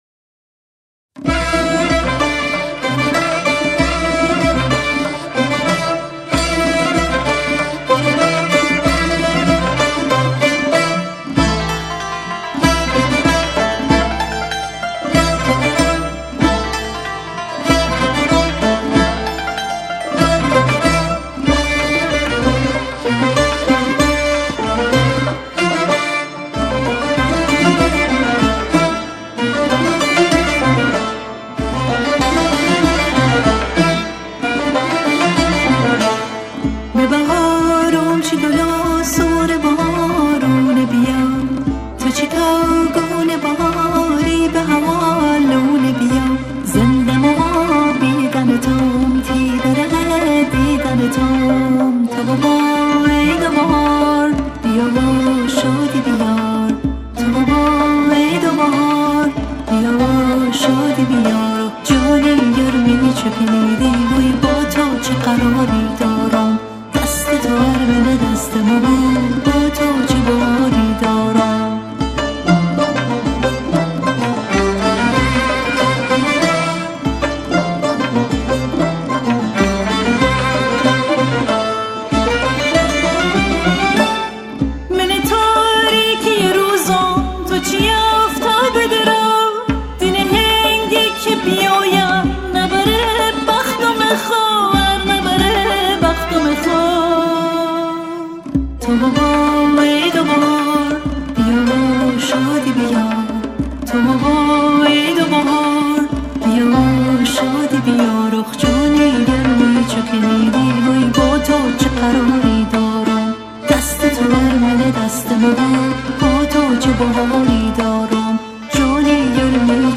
موزیک ها بر اساس سبک ها » پاپ فارسی و محلی »